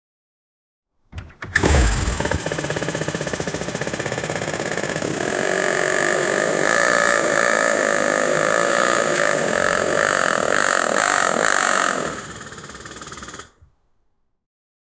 Failures Sound Effects - Free AI Generator & Downloads
failed-attempt-to-start-a-wtohf4ms.wav